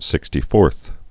(sĭkstē-fôrth)